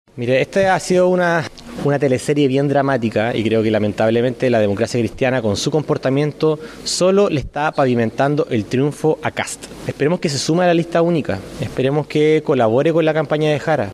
En la misma línea, el diputado Diego Ibáñez (FA) fue un paso más allá y señaló que el comportamiento de la Democracia Cristiana le está “pavimentando el triunfo a José Antonio Kast”.